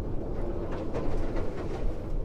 static.ogg